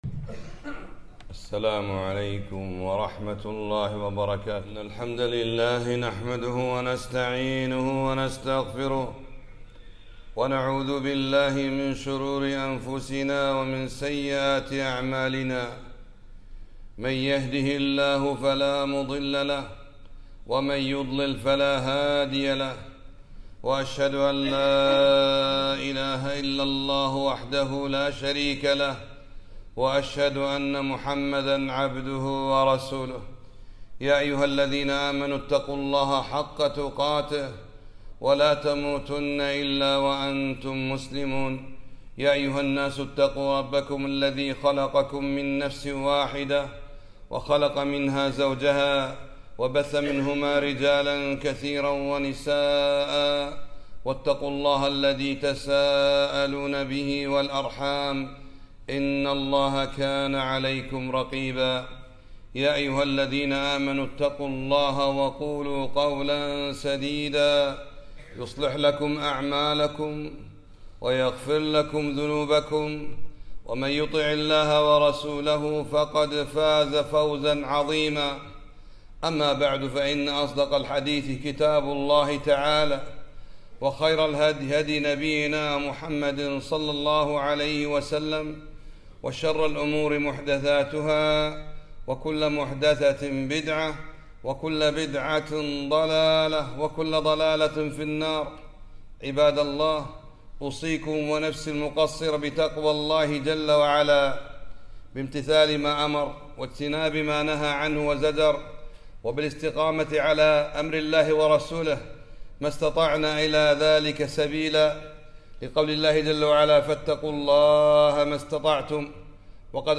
خطبة - فضائل كلمة التوحيد ( لا إله إلا الله)